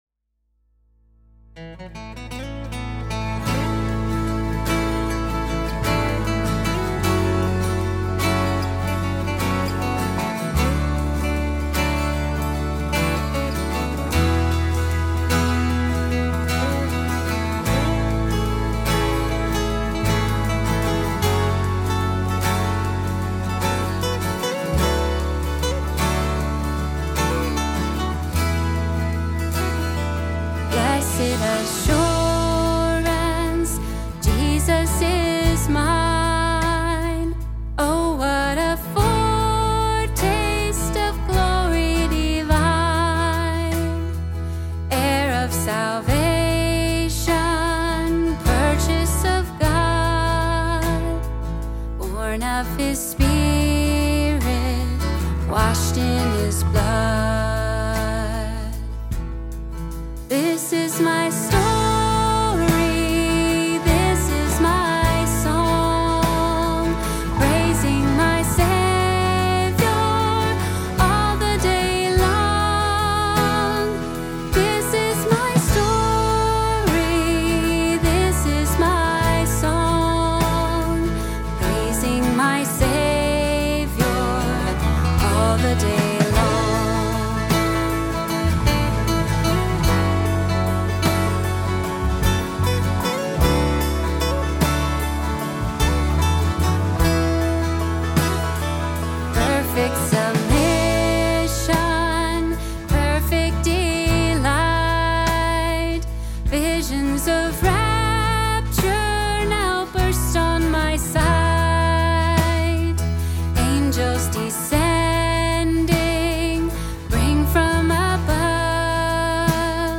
Our service begins with the singing one of two hymns, the more contemporary song being found here and the traditional hymn being here.
Welcome to this time of worship.